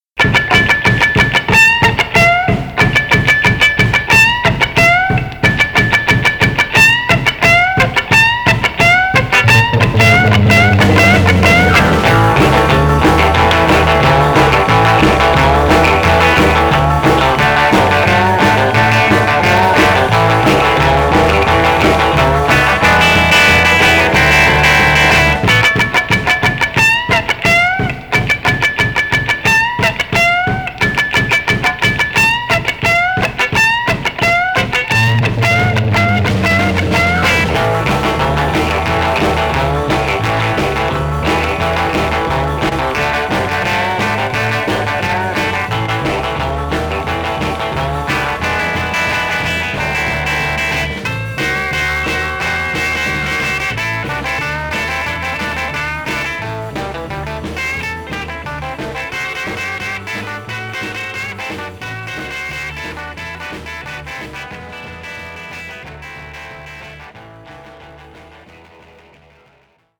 the quirky and fun